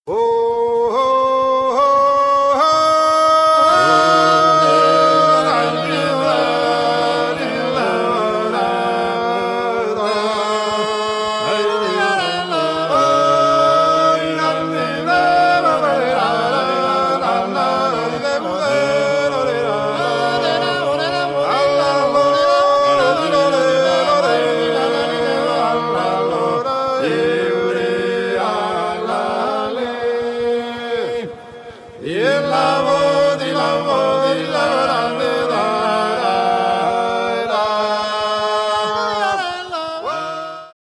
Nagranie koncertowe.